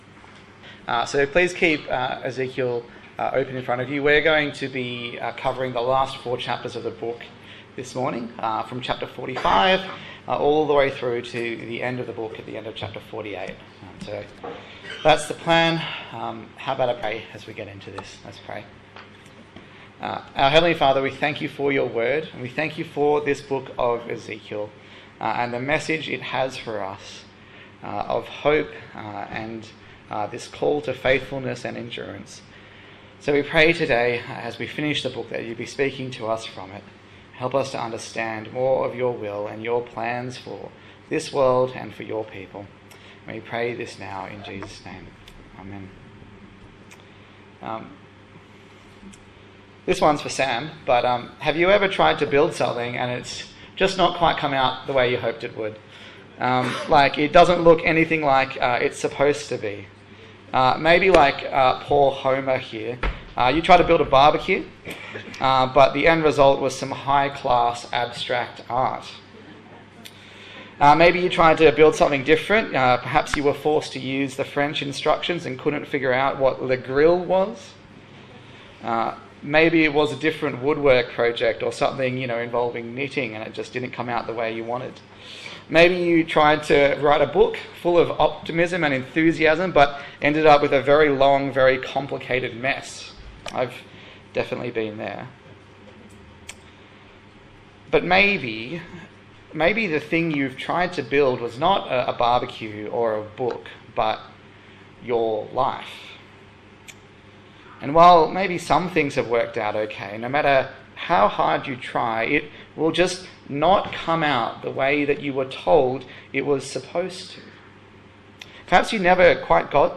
Ezekiel Passage: Ezekiel 45 to 48 Service Type: Sunday Morning